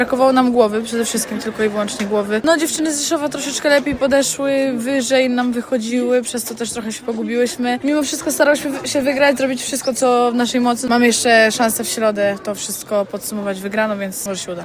– skomentowała jedna z zawodniczek